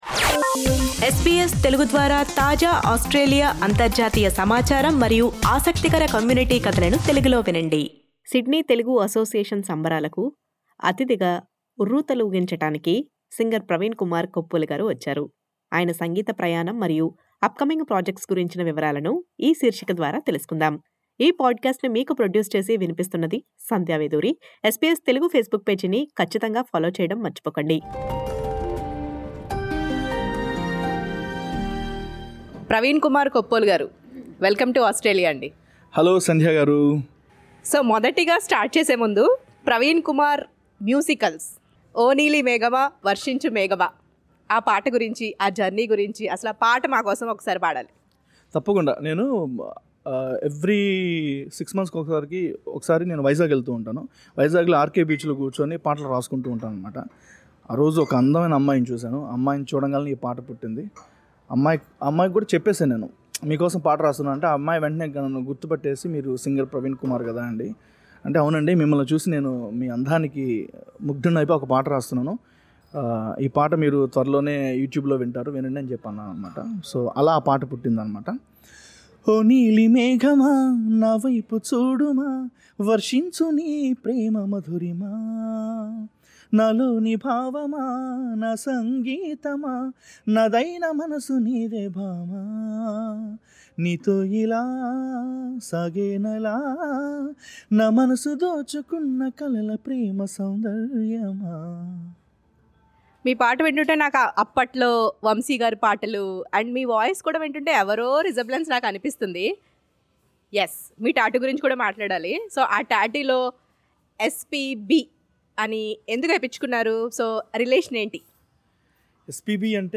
స్పెషల్ ఇంటర్వ్యూ